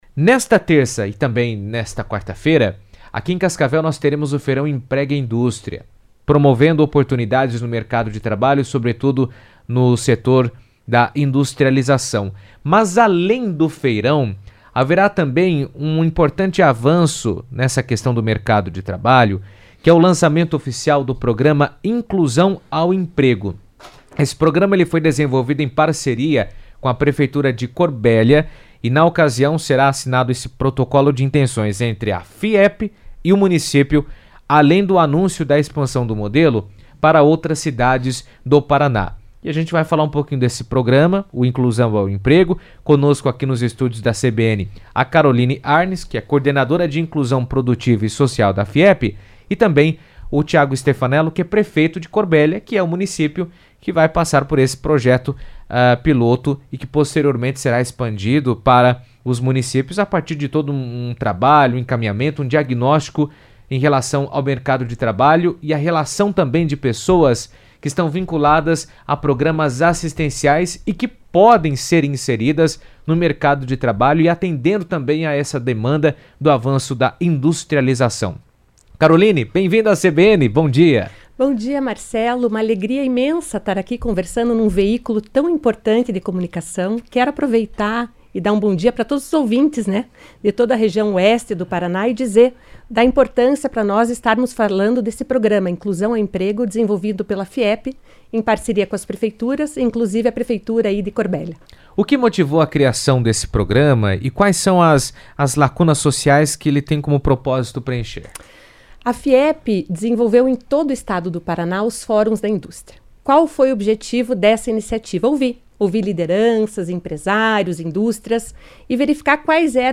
Durante a conversa, eles detalharam a proposta, que visa gerar oportunidades para famílias em situação de vulnerabilidade e deve ser expandida para outros municípios do Paraná.